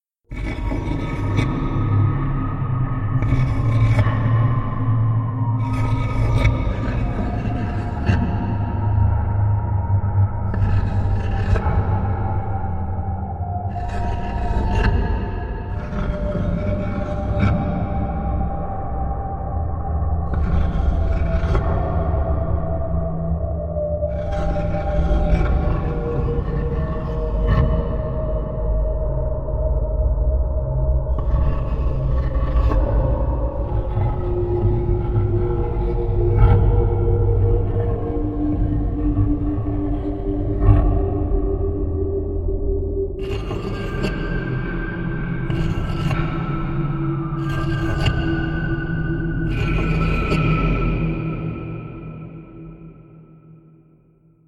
На этой странице собраны загадочные звуки пирамид – от глухого эха в узких проходах до мистического гула древних сооружений.
Звук в пирамиде с попытками открыть разные двери